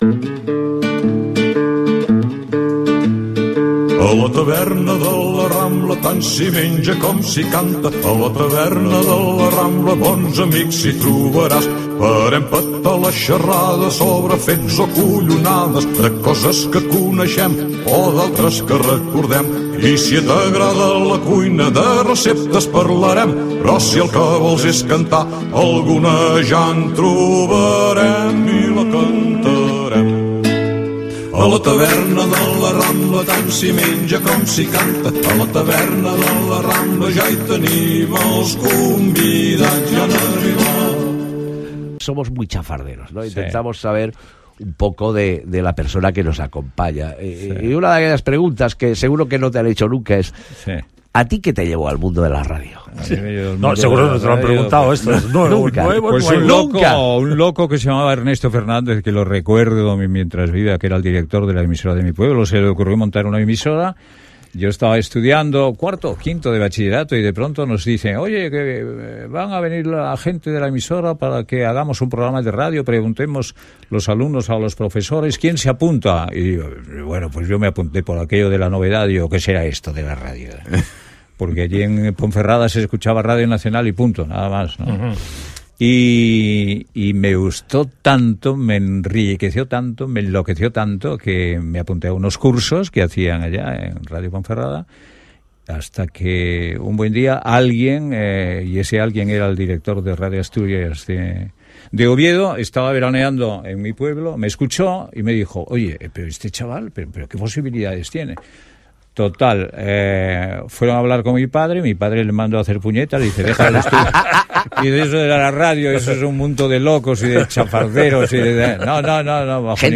Sintonia del programa, entrevista a Luis del Olmo sobre els seus inicis a la ràdio i la seva arribada a Barcelona.
Info-entreteniment